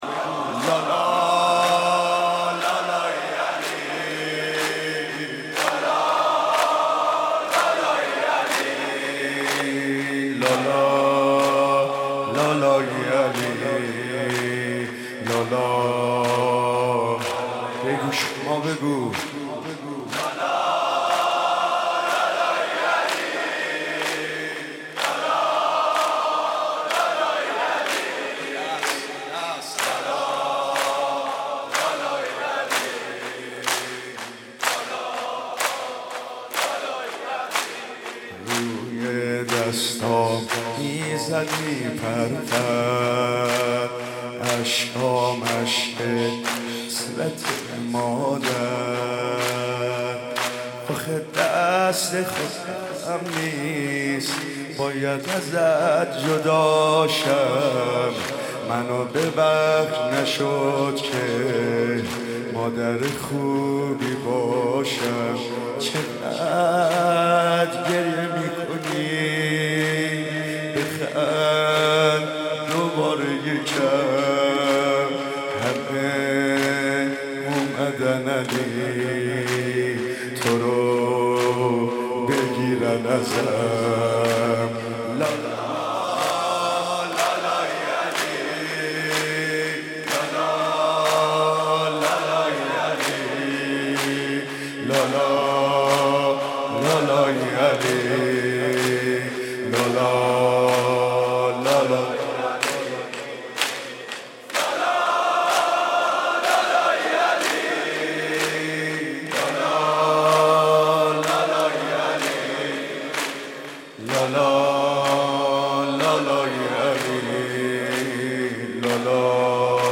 دانلود مداحی
شب 7 محرم95